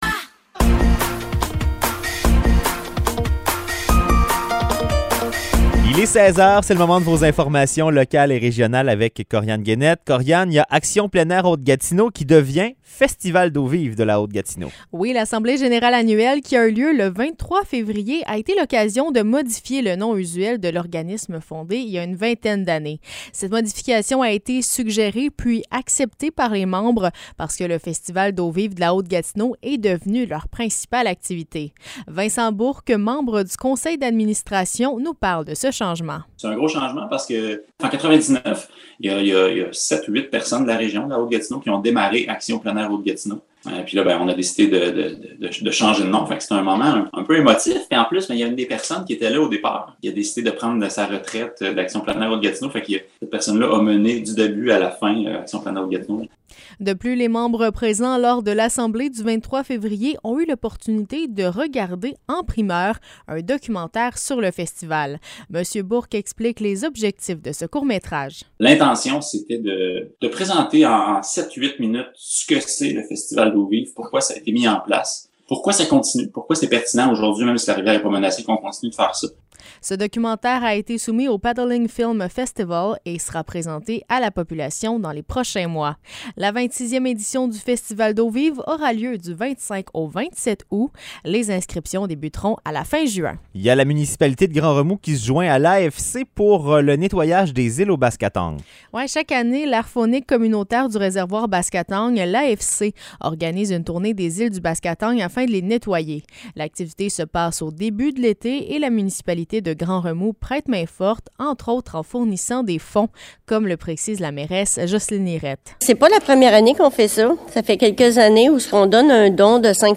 Nouvelles locales - 9 mars 2023 - 16 h